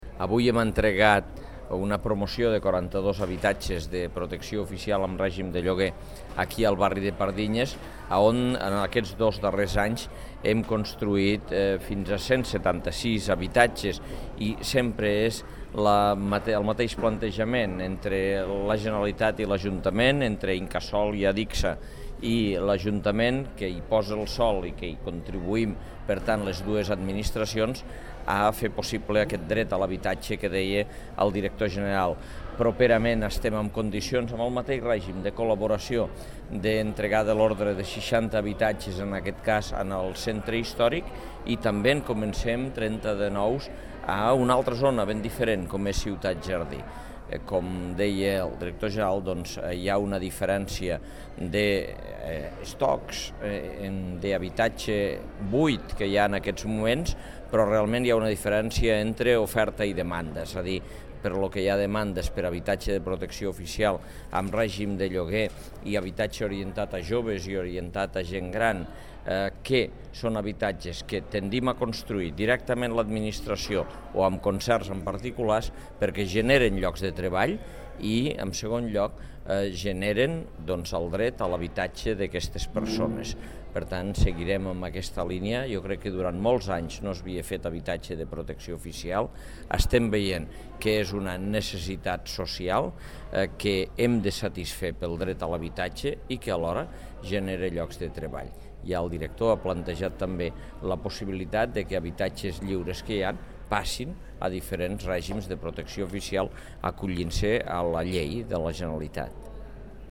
arxiu-de-so-de-lalcalde-de-lleida-sobre-el-lliurament-de-les-claus-dels-habitatges